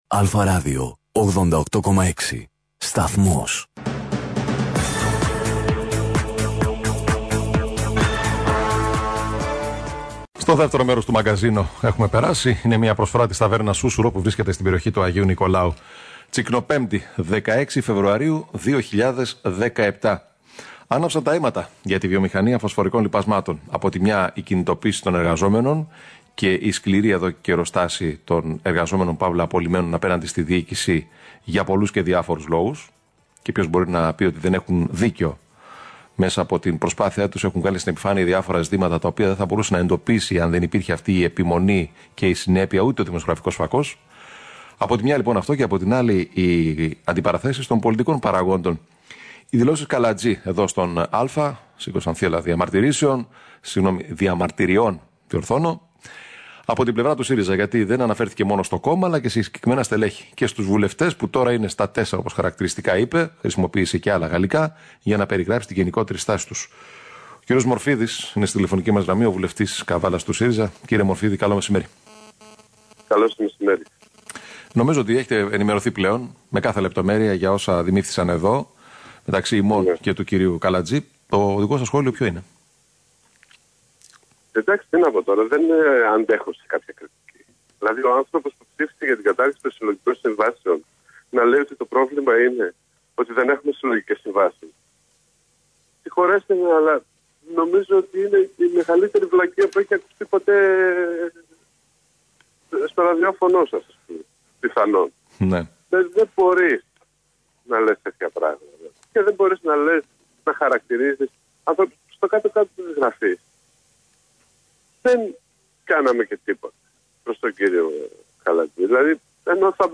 Μιλώντας την Πέμπτη 16 Φεβρουαρίου 2017 στο Κεντρικό Μαγκαζίνο του σταθμού, ο βουλευτής του ΣΥΡΙΖΑ Καβάλας υπογράμμισε για άλλη μια φορά ότι η κυβέρνηση εργάζεται προς την κατεύθυνση εξεύρεσης μιας οριστικής και βιώσιμης λύσης για τη Βιομηχανία Φωσφορικών Λιπασμάτων κι ότι αυτή η λύση ΔΕΝ θα περιλαμβάνει τον κ. Λαυρεντιάδη!